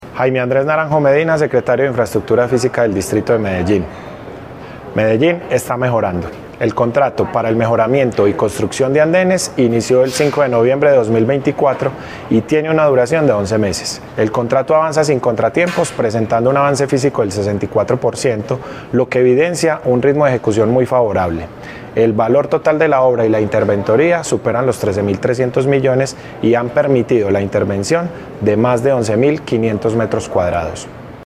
Declaraciones-secretario-de-Infraestructura-Fisica-Jaime-Andres-Naranjo-Medina.mp3